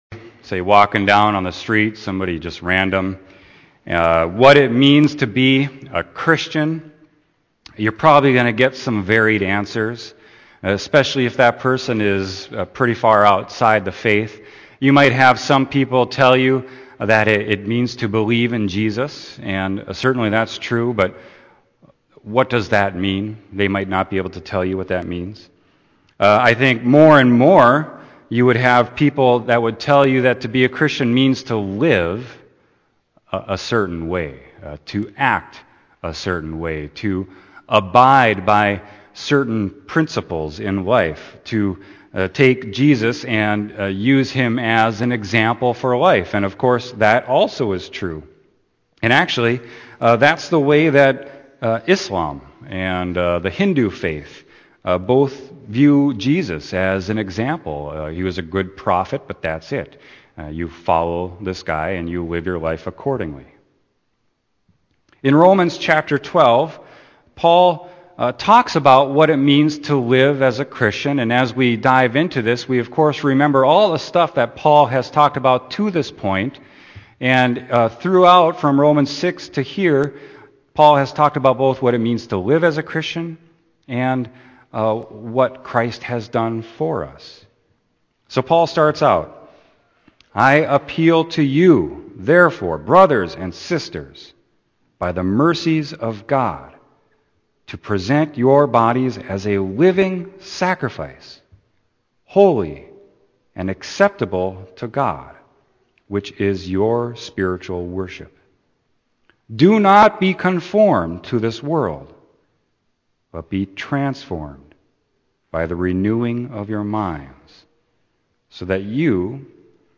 Sermon: Romans 12.1-8